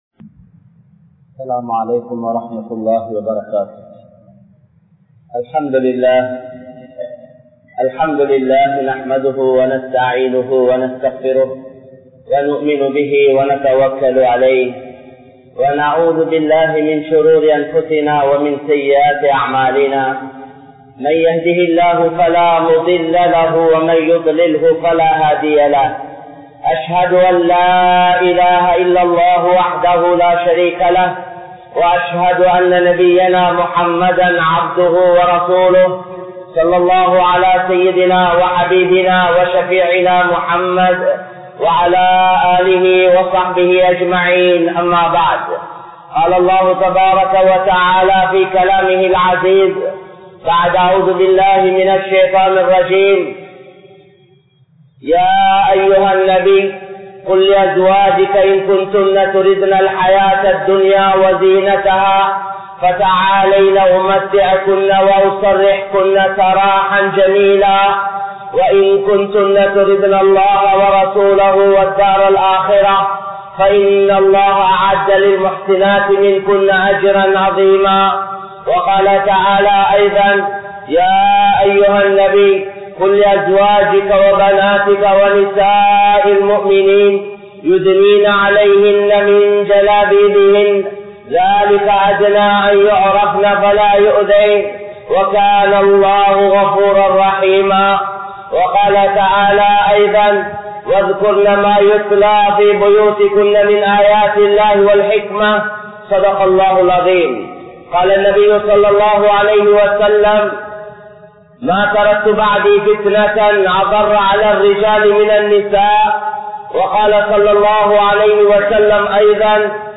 Soathanaihalin Vahaihal | Audio Bayans | All Ceylon Muslim Youth Community | Addalaichenai
Junction Jumua Masjith